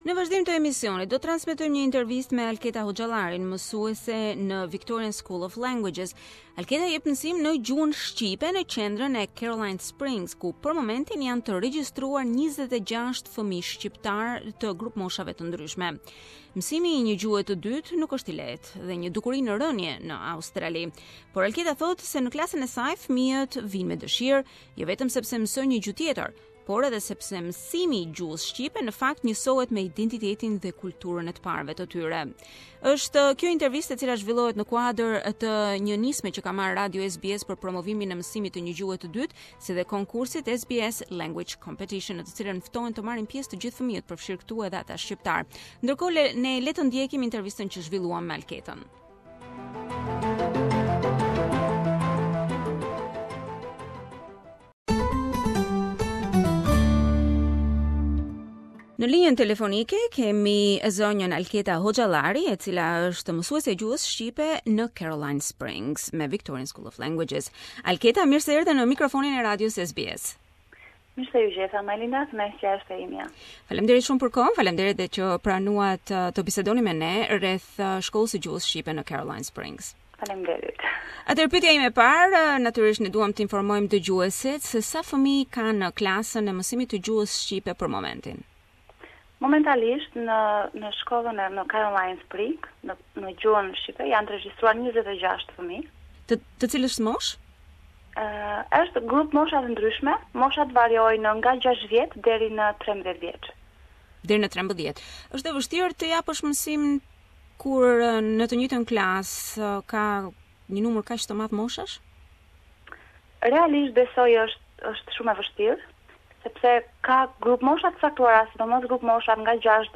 Recent studies show that second language skills are on the decline in Australia and at the same time it is well established that learning another language is beneficial in terms of physical and emotional development. Learning a second language is exciting and beneficial at all ages. We interviewed